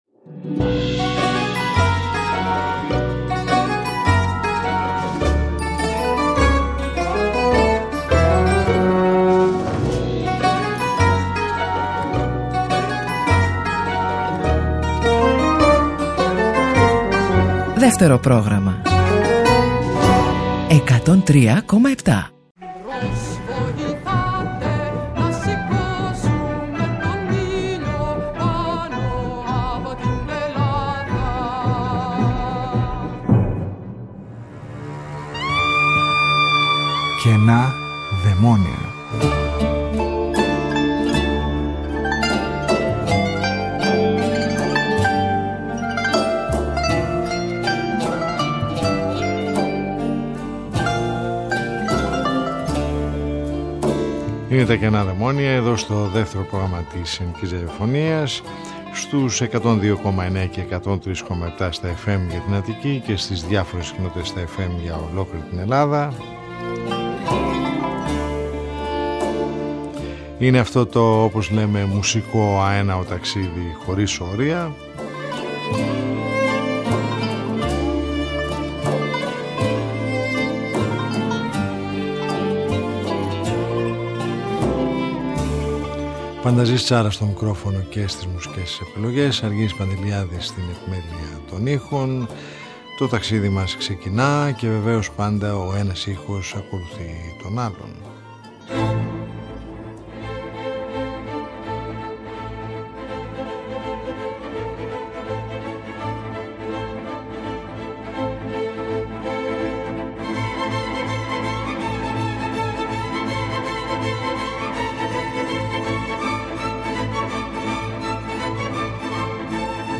Μια ραδιοφωνική συνάντηση κάθε Σάββατο στις 22:00 που μας οδηγεί μέσα από τους ήχους της ελληνικής δισκογραφίας του χθες και του σήμερα σε ένα αέναο μουσικό ταξίδι.